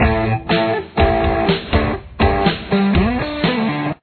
1st Intro Riff
Here’s what it sounds like at full speed (122 bpm):